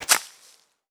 Bushes.wav